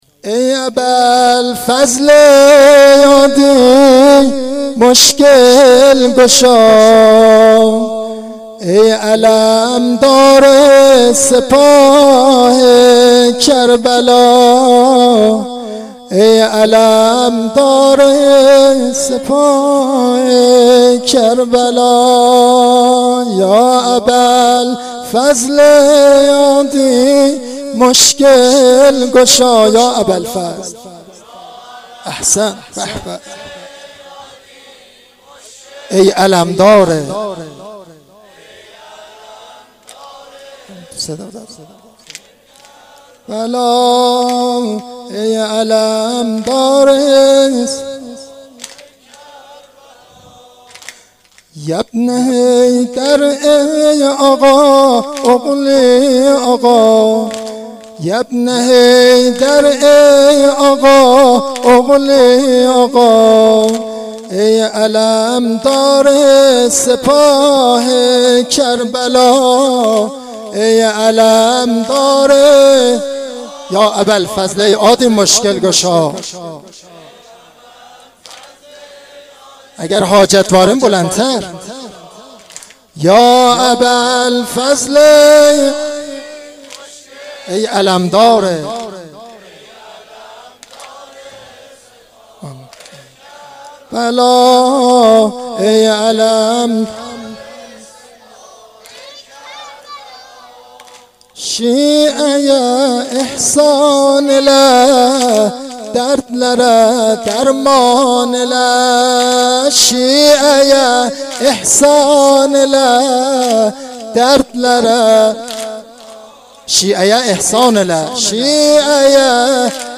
نوحه شب هفتم محرم93
Noheh-Shabe-07-moharram93.mp3